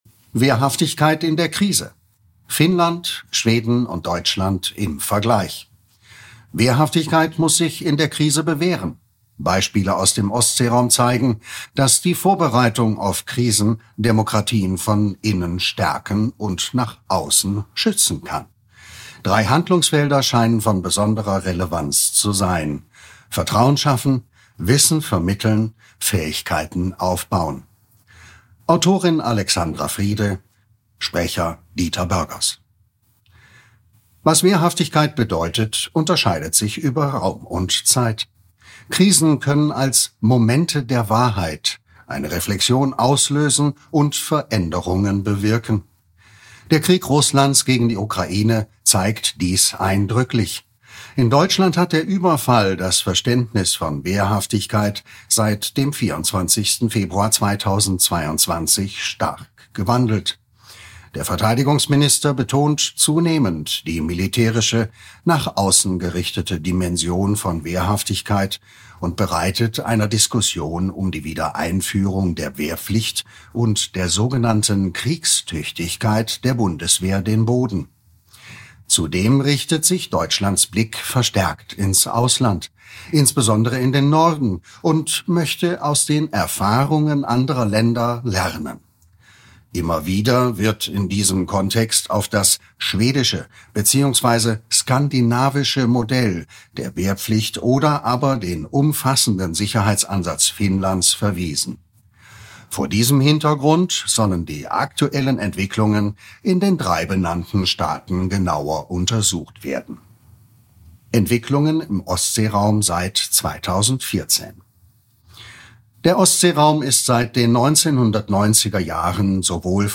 Hörfassung des Beitrags